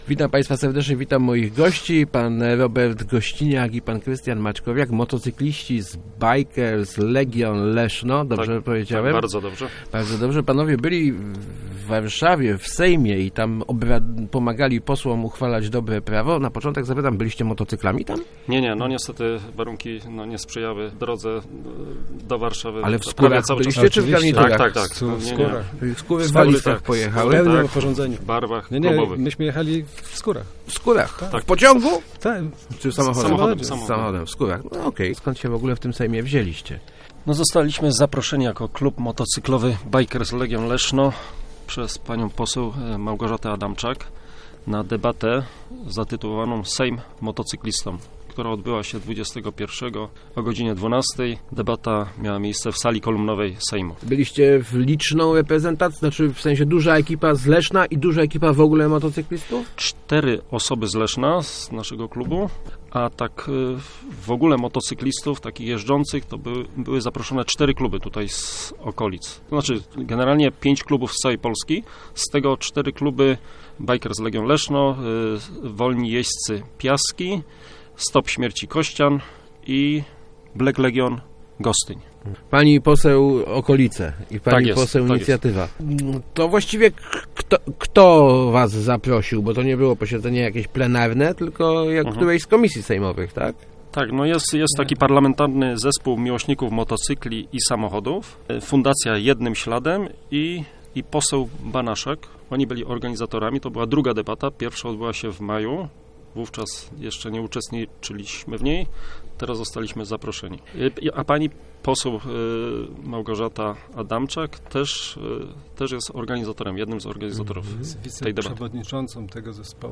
mówili w Rozmowach Elki